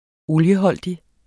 olieholdig adjektiv Bøjning -t, -e Udtale [ ˈoljəˌhʌlˀdi ] Betydninger 1. som indeholder spiselig planteolie Linolie fremstilles af hørplantens olieholdige frø Wikipedia2008 Wikipedia (internetleksikon), 2008.